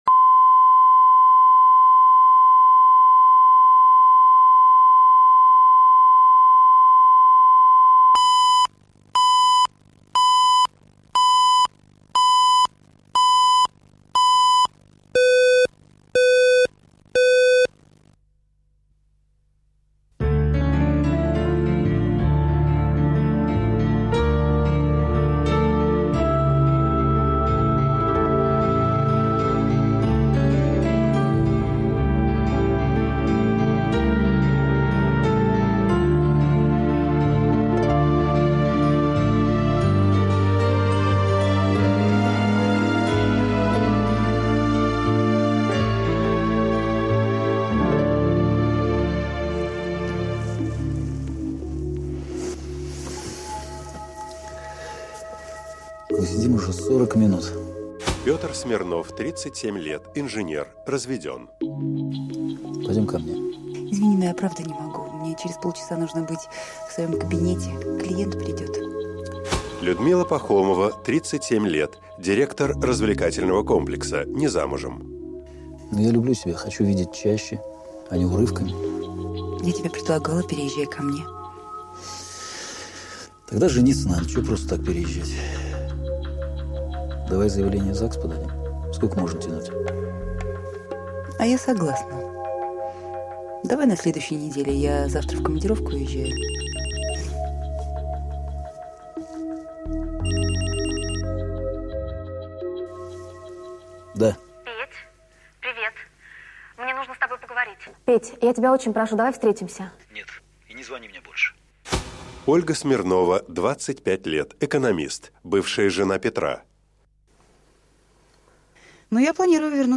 Аудиокнига Я без тебя не могу | Библиотека аудиокниг